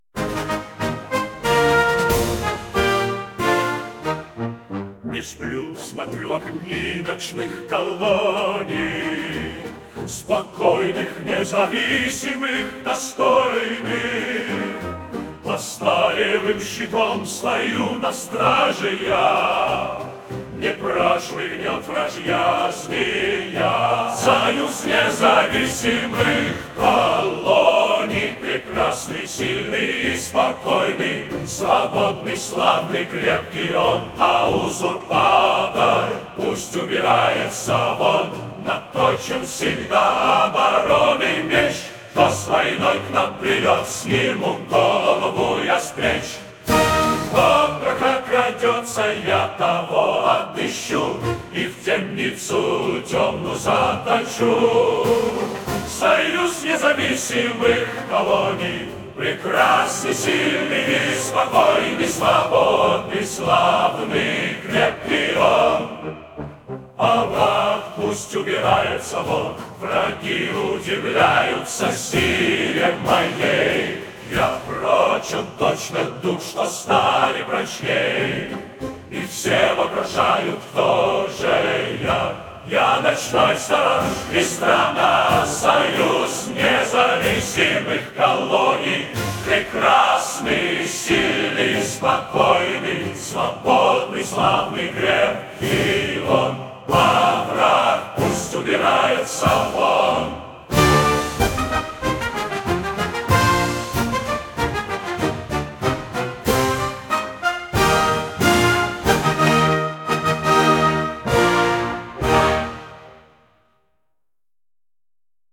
Gimn_SNK_man.ogg